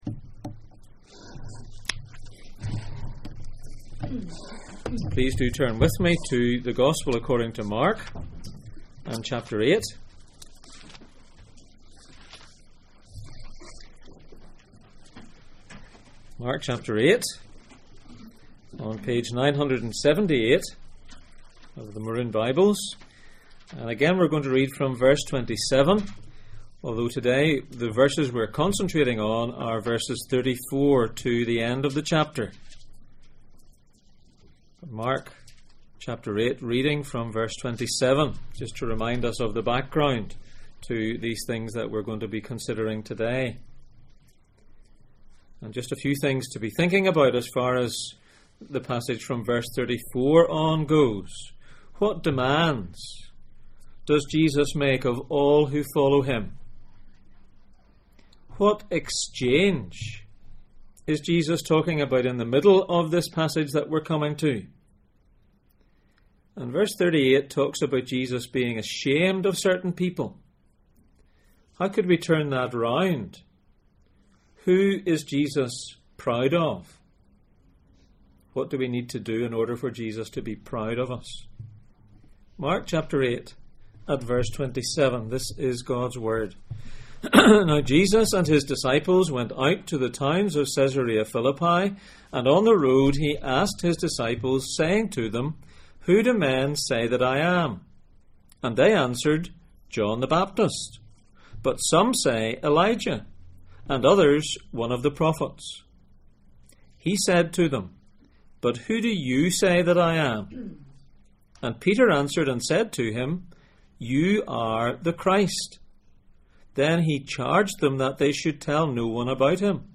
Jesus in Mark Passage: Mark 8:27-9:1 Service Type: Sunday Morning %todo_render% « Jesus and the cross